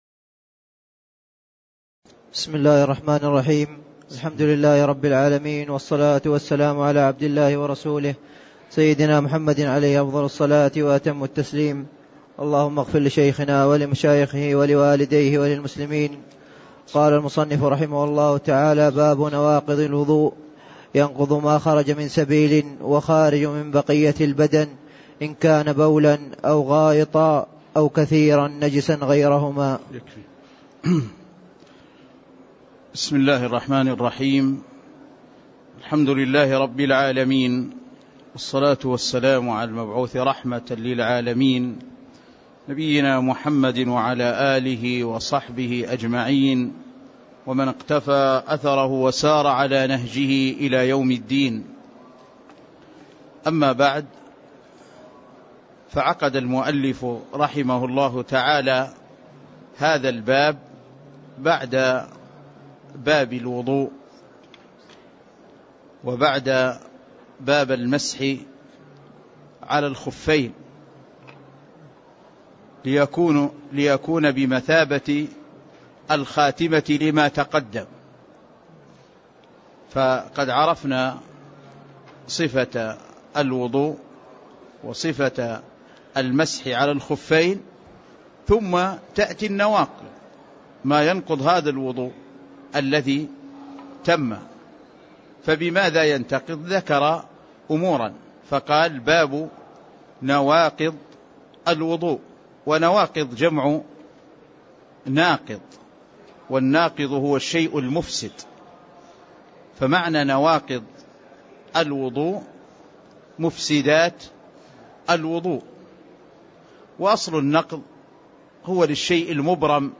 تاريخ النشر ٢٩ جمادى الأولى ١٤٣٥ هـ المكان: المسجد النبوي الشيخ